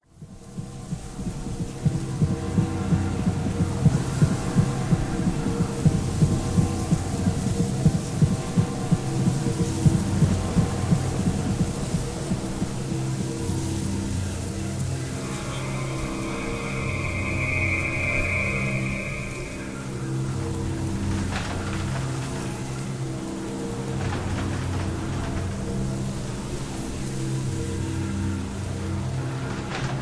Halloween movie soundscape